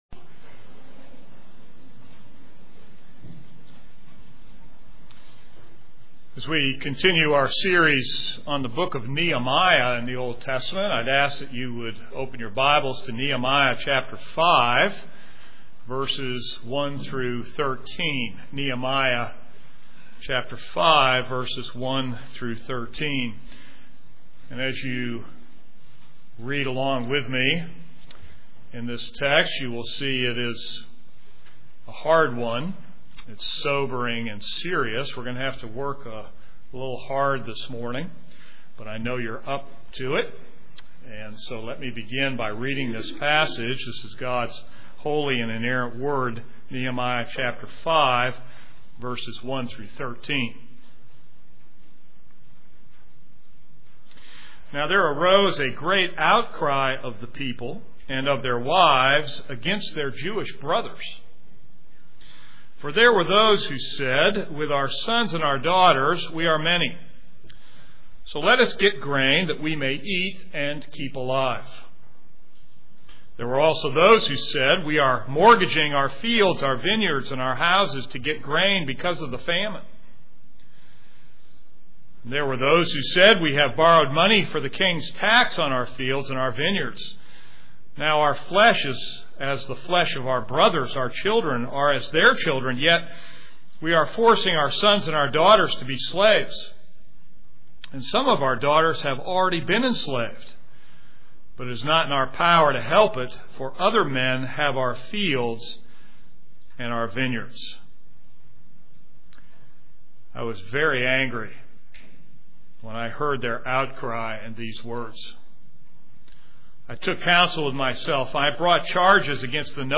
This is a sermon on Nehemiah 5:1-13.